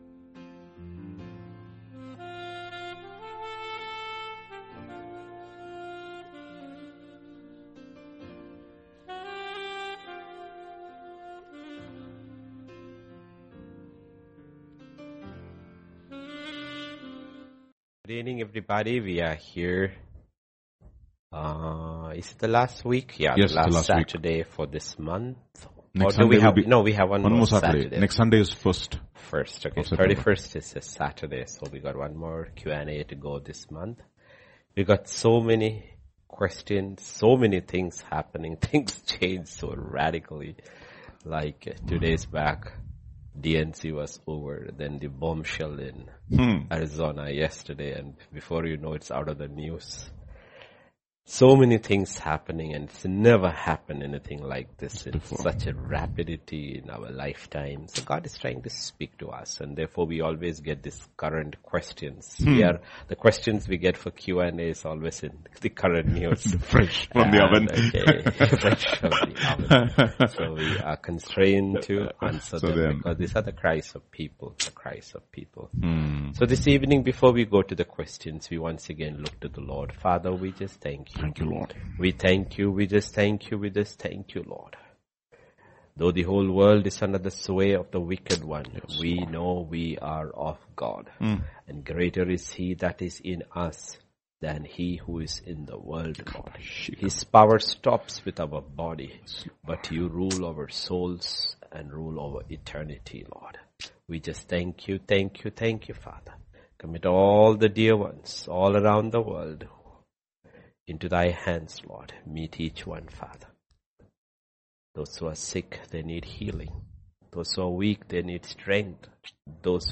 Similar to Grace Tabernacle Church Hyderabad - Sermons